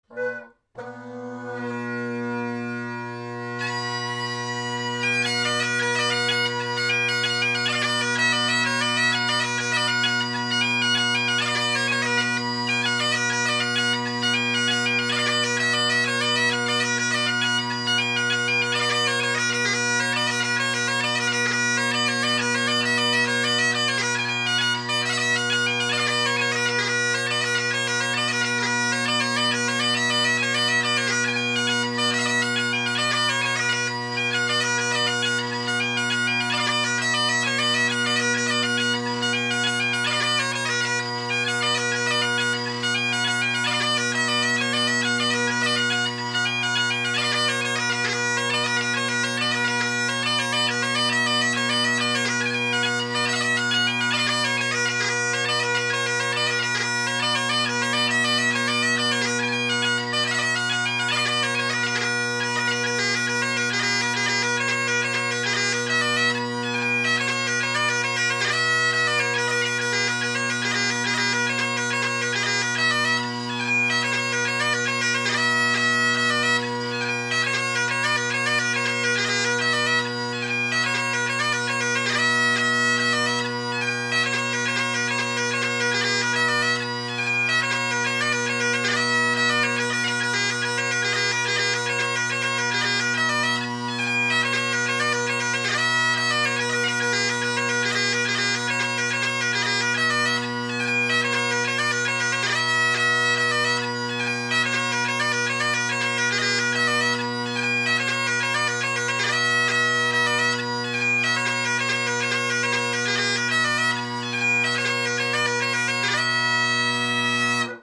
Great Highland Bagpipe Solo
My drones here are obviously still tuned to low A and I’m not using a Bagad chanter.